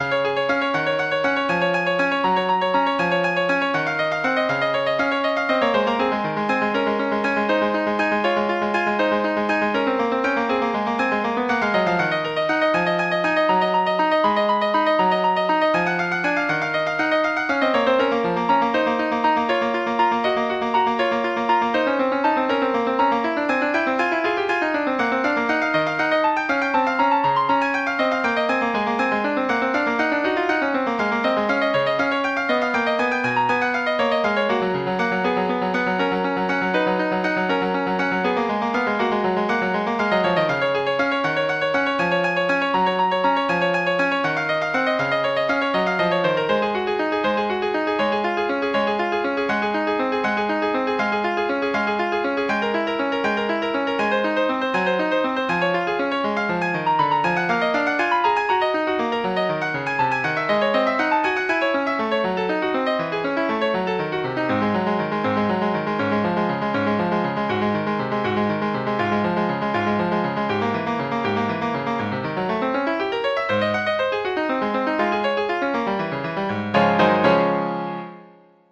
Classical Bach, Johann Sebastian Praeludium III BWV 847 Piano version
Piano Classical Piano Classical Piano Free Sheet Music Praeludium III BWV 847